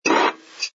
sfx_pick_up_bottle08.wav